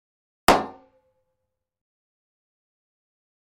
Звуки удара металлической трубой
Удар арматурой по металлической поверхности звук